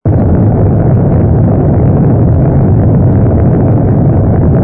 ge_s_thruster_03.wav